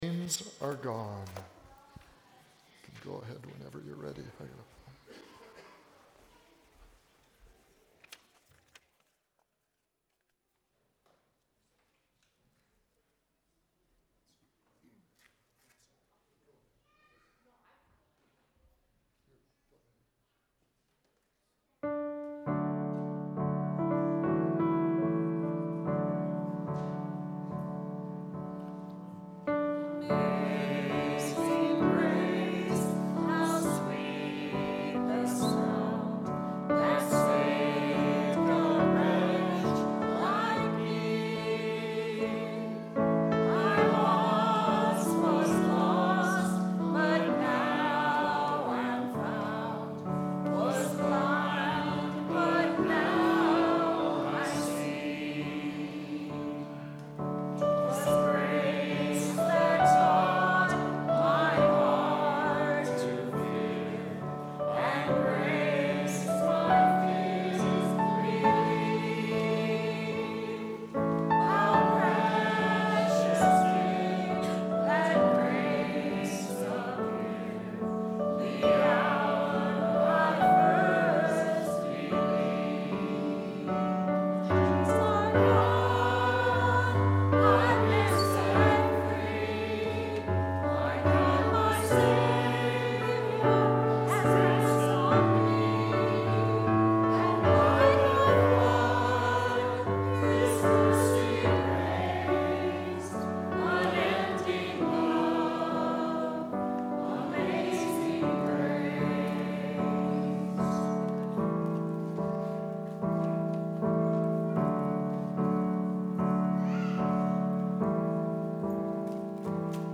Bible Text: Isaiah 58:5-9a | Preacher: Guest Speaker: